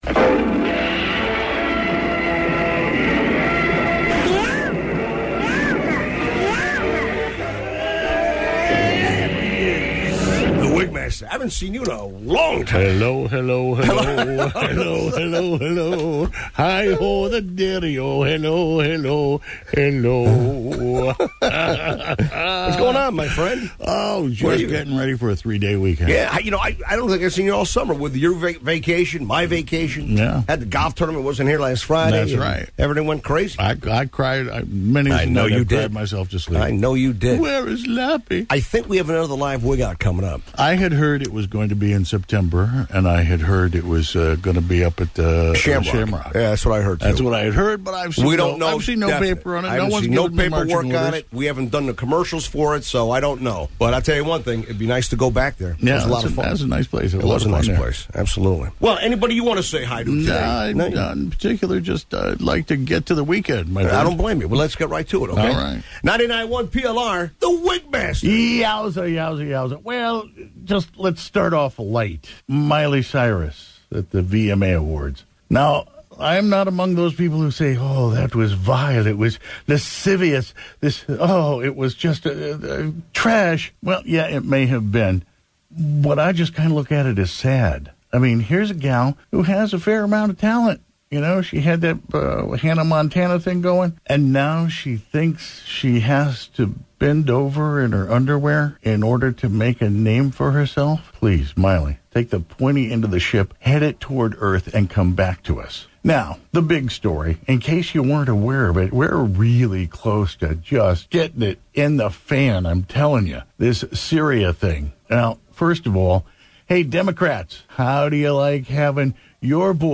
Live Wigout